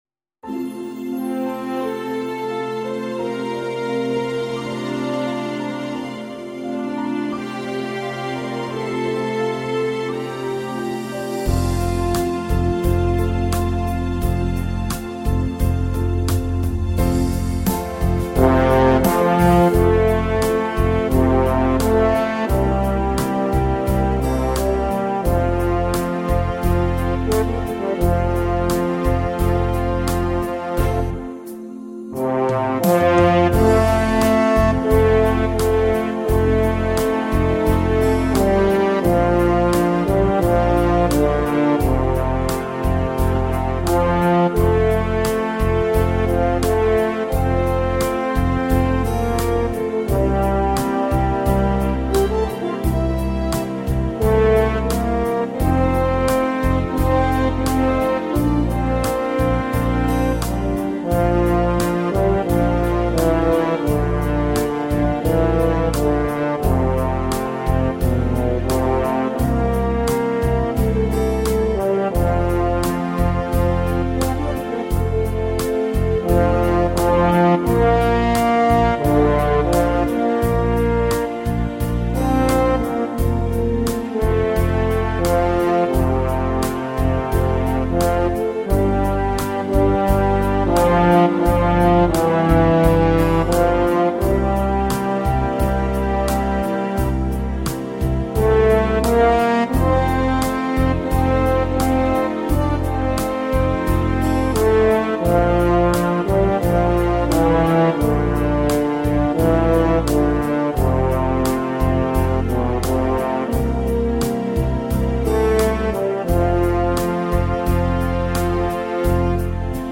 Hintergrundmusik für den Film Würzburg